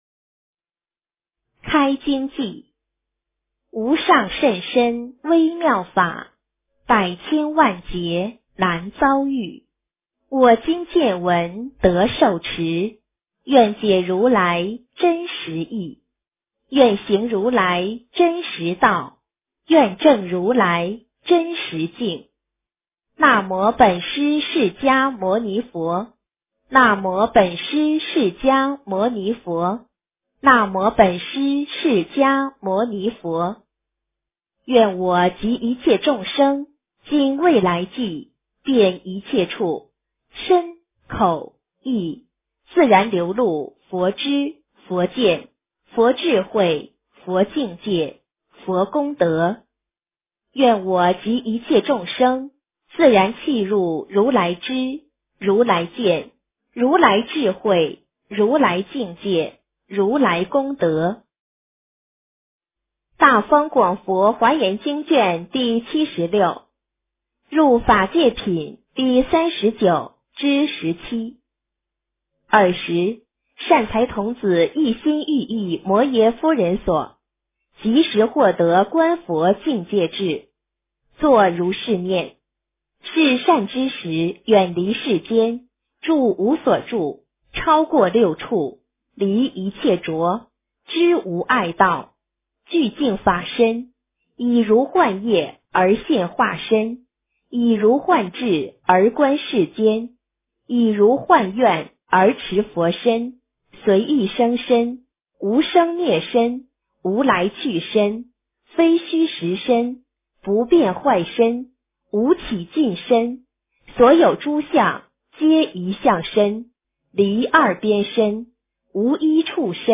华严经76 - 诵经 - 云佛论坛